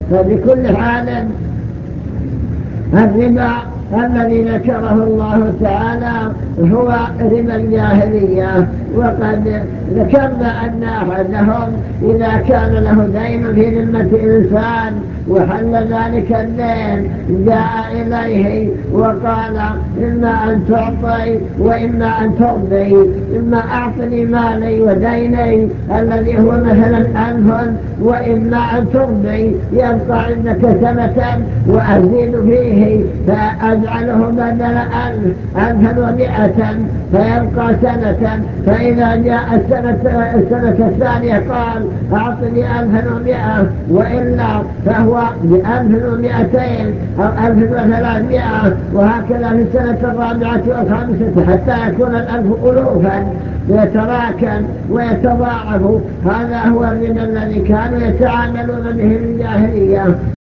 المكتبة الصوتية  تسجيلات - محاضرات ودروس  الربا وما يتعلق به من أحكام الربا